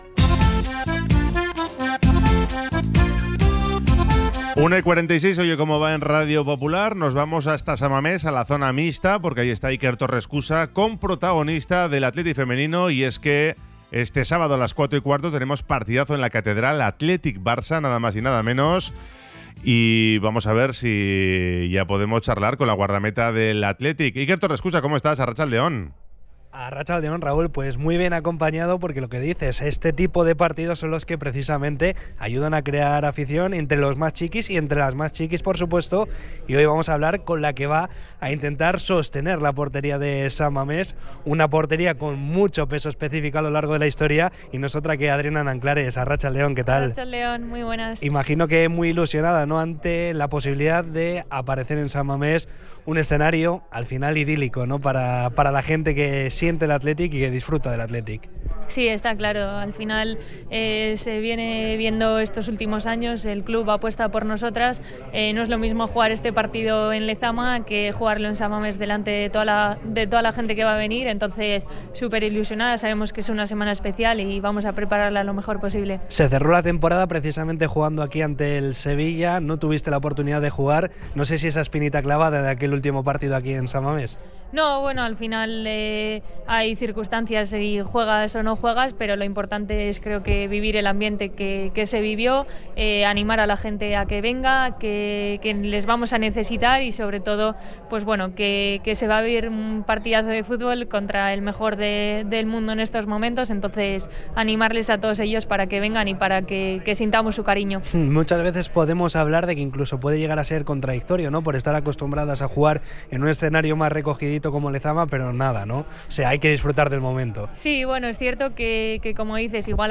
Entrevista con la guardameta del Athletic Club sobre la actualidad rojiblanca